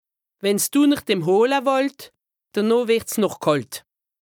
Haut Rhin
Ville Prononciation 68
Bruebach